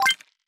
Coins (20).wav